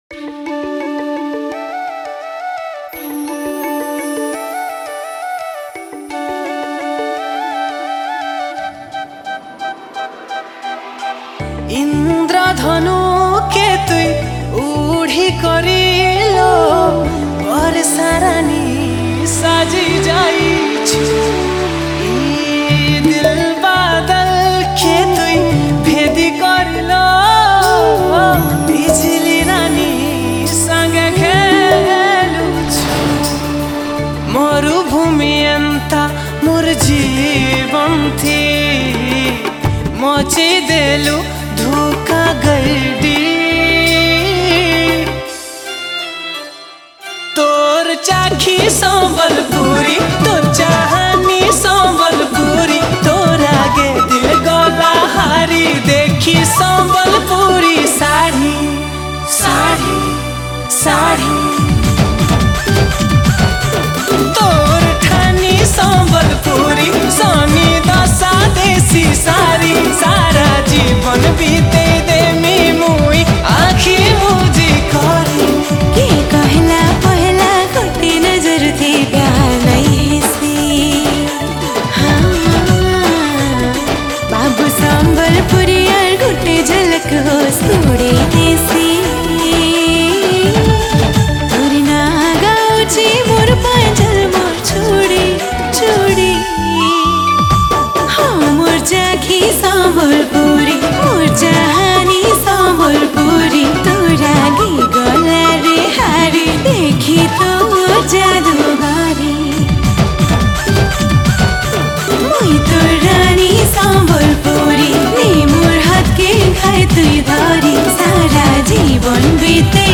New Sambalpuri Song 2022 Artist